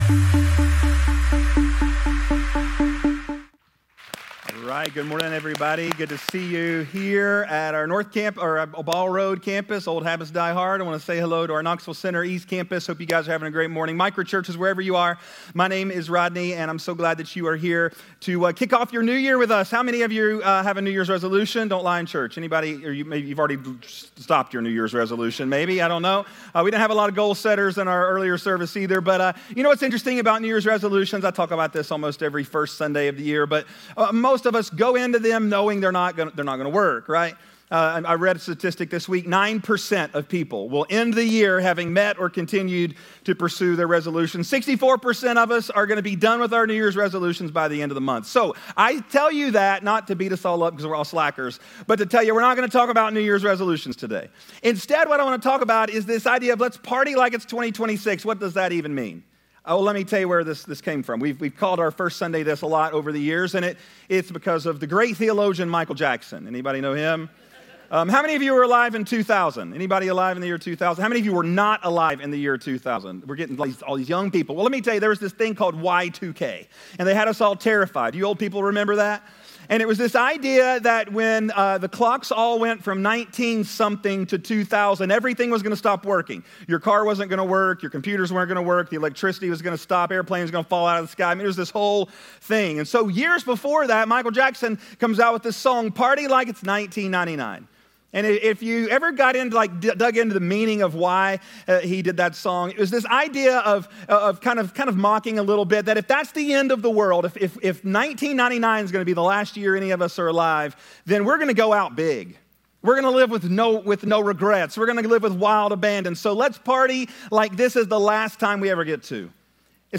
MICRO CHURCH GUIDE January 04, 2026 YouVersion Sermon Page Podcast Audio _____________________ ANNOUNCEMENTS Seek First - January 1st - 31st As we begin 2026, we’re starting with prayer.